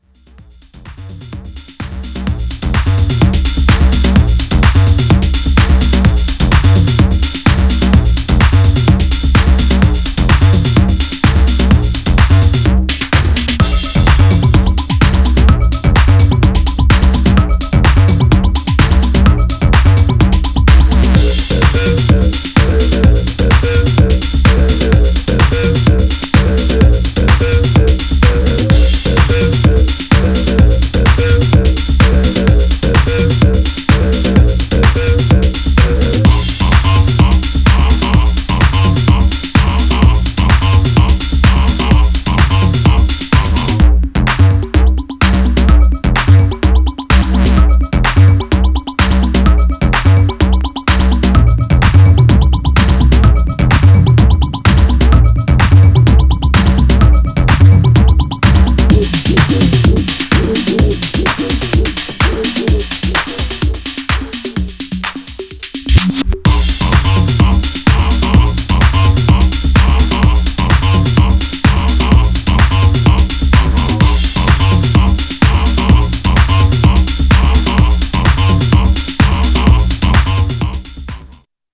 Styl: Electro, House, Techno